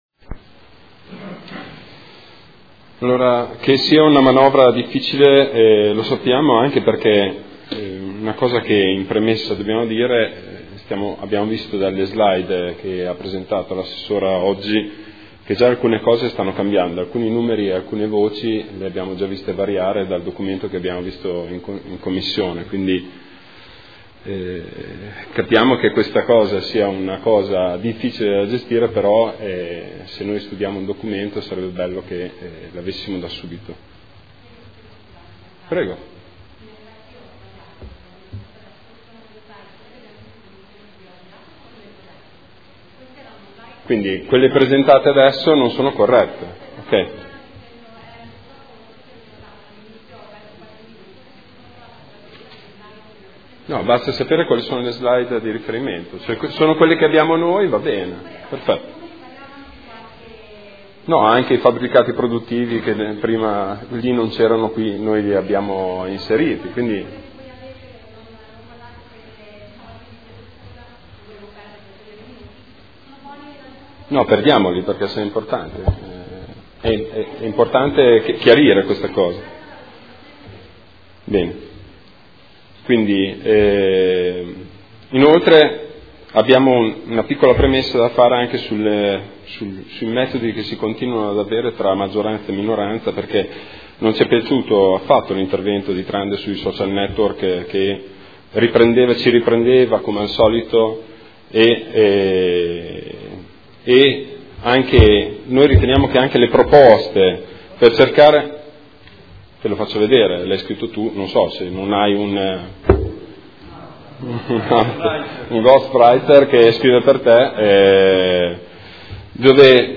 Seduta del 29/01/2015. Documento Unico di Programmazione 2015/2019 – Sezione strategica. Dibattito